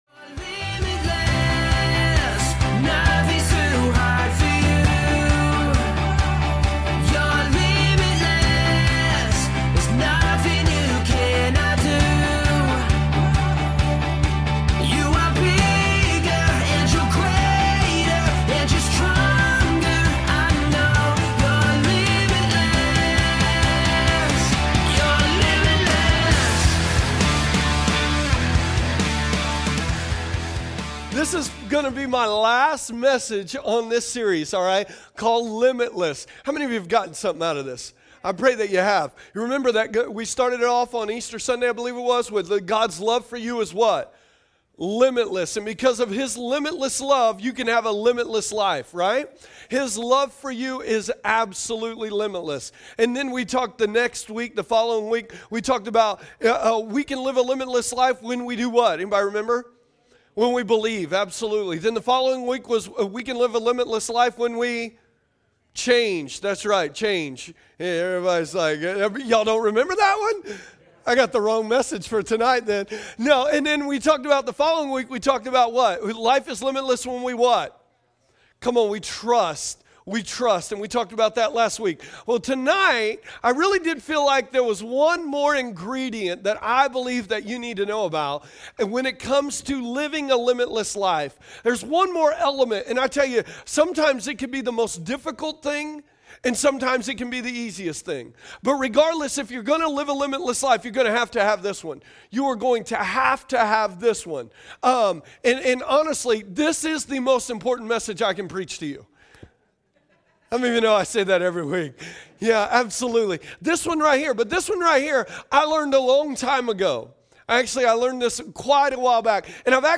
2015 Category: Sermons ...